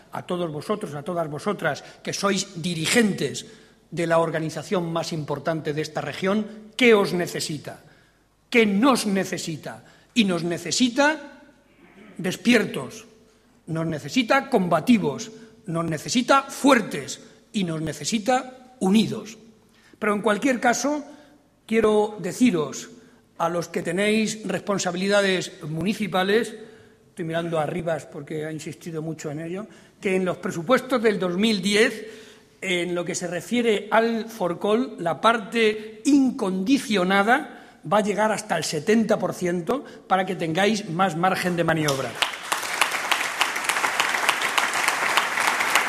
Barreda hizo estas declaraciones durante su intervención en el Comité regional del PSCM-PSOE, en Toledo.
Corte sonoro Barreda Comité Regional